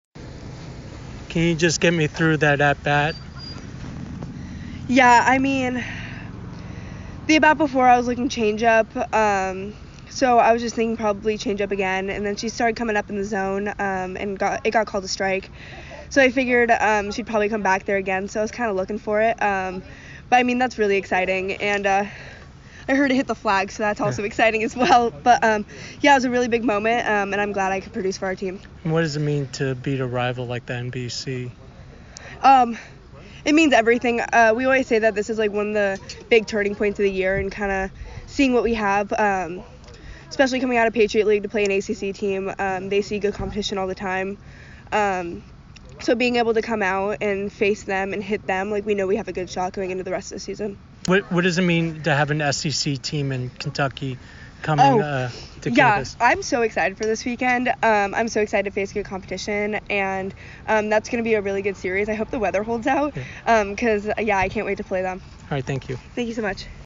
Boston College Postgame Interview